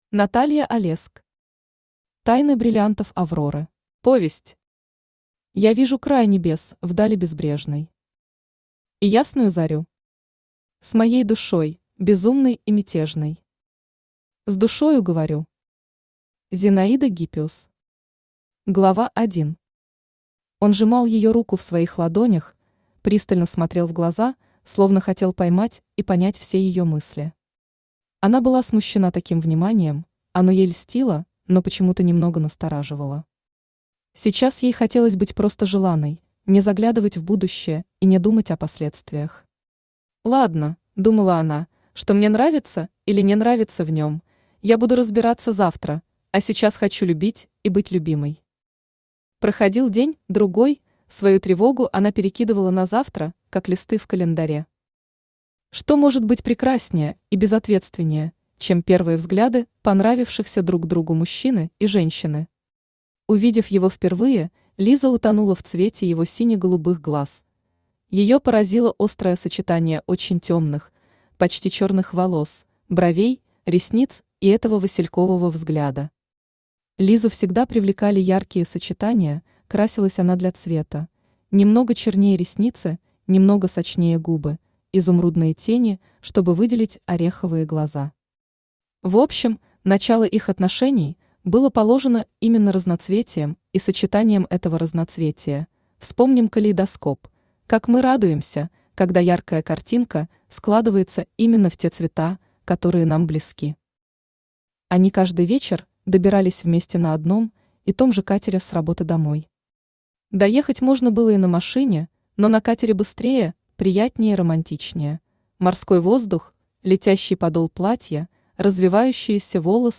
Аудиокнига Тайны бриллиантов Авроры | Библиотека аудиокниг
Aудиокнига Тайны бриллиантов Авроры Автор Наталья Олеск Читает аудиокнигу Авточтец ЛитРес.